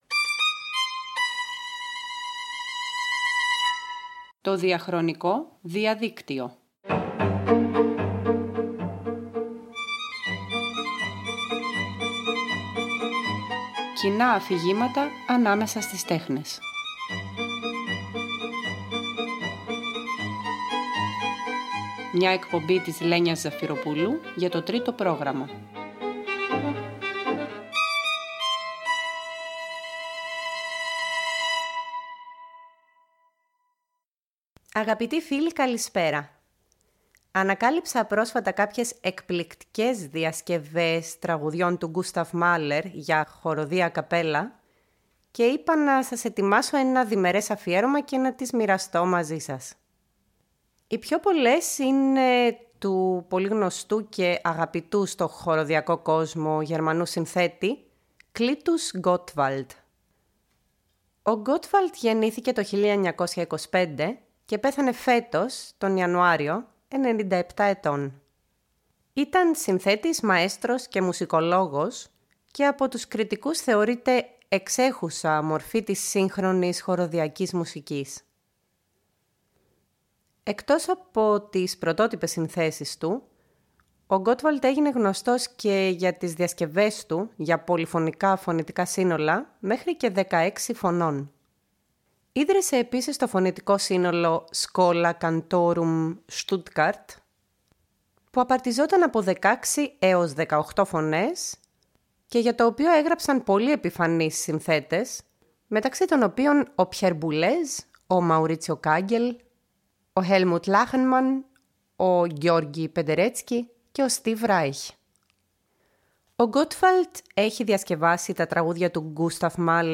Ένα αφιέρωμα στις διασκευές για ασυνόδευτη χορωδία που ο Clytus Gottwald και ο Gérard Pesson έχουν κάνει σε τραγούδια και συμφωνική μουσική του Gustav Mahler.
Και όταν η πρώτη σοπράνο αναλαμβάνει τις πολύ ψηλές γραμμές του φλάουτου ή των βιολιών, η αρχική μελωδία του τραγουδιστή χάνεται στο δεύτερο ή και τρίτο επίπεδο της νέας παρτιτούρας, σαν ρυάκι που διατρέχοντας ένα γεωγραφικό ανάγλυφο γίνεται για λίγο υπόγειο.